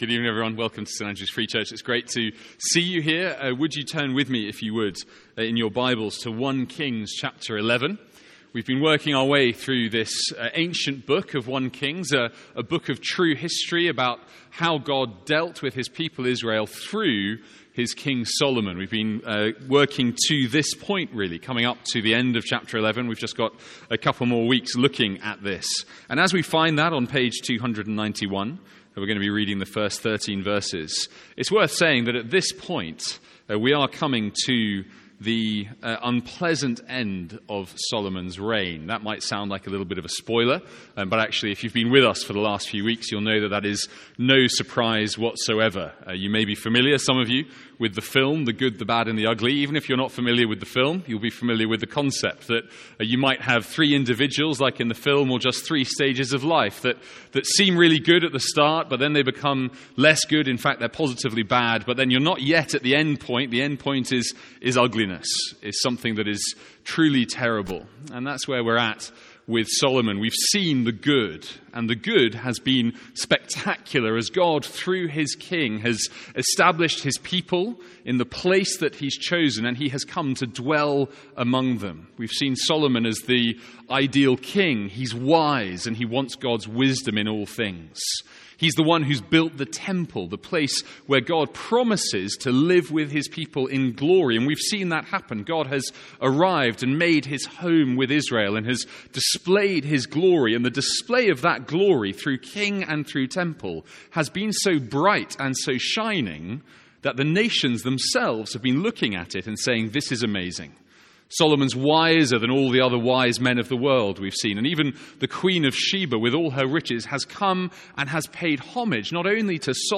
Sermons | St Andrews Free Church
From our evening series in 1 Kings.